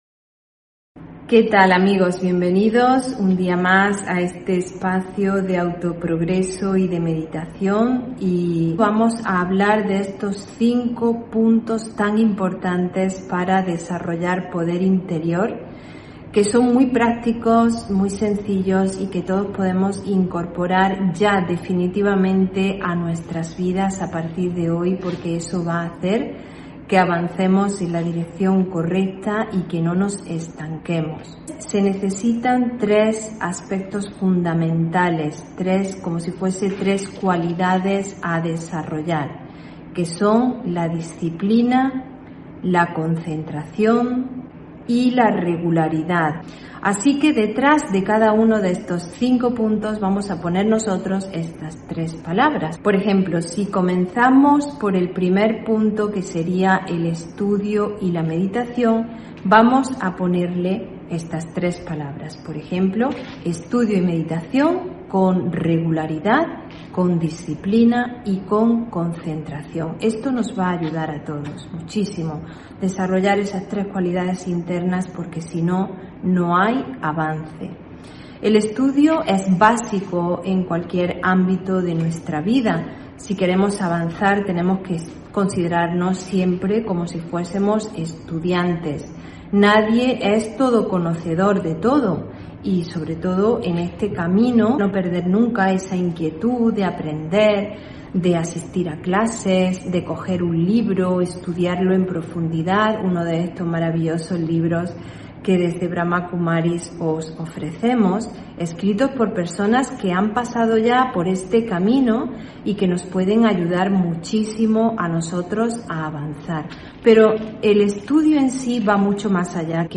Meditación y conferencia: Poder interior (25 Abril 2022)